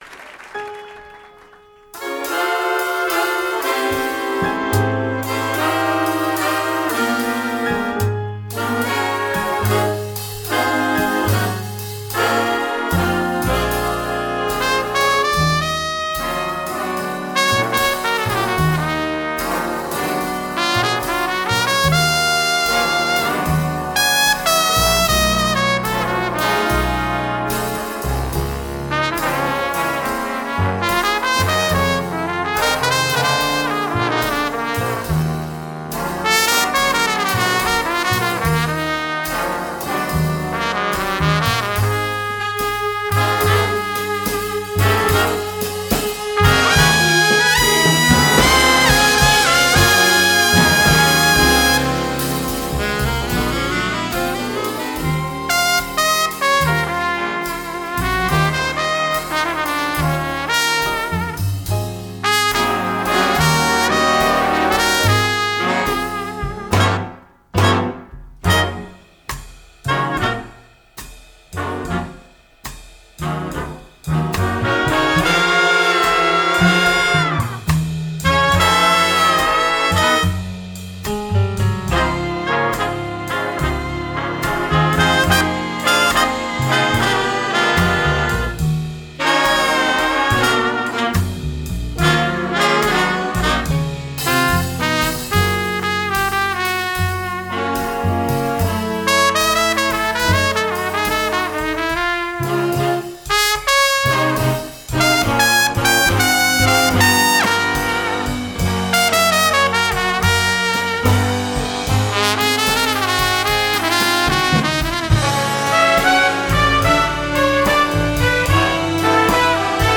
Home > Music > Blues > Medium > Floating > Sneaking